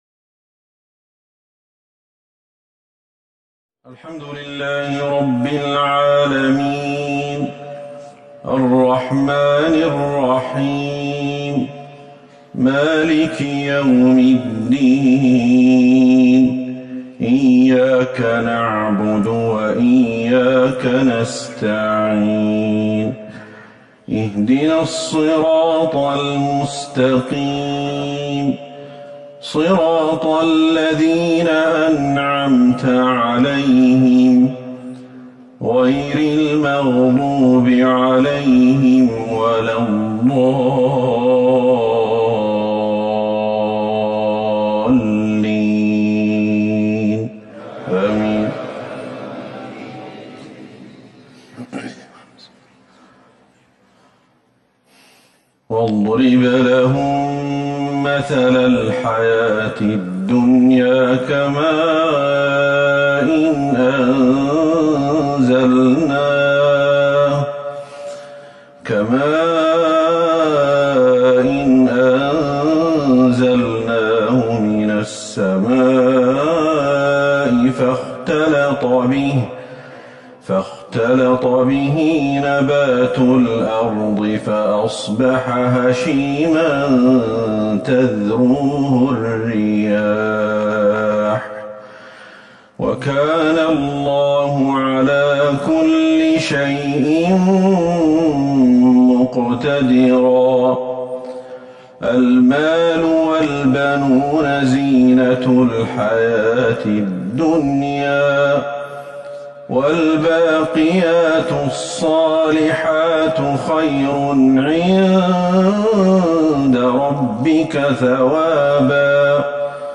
صلاة العشاء ٢٠ جمادى الاولى ١٤٤١هـ من سورة الكهف Evening prayer 8-1-2020 from cave Sora > 1441 هـ > الفروض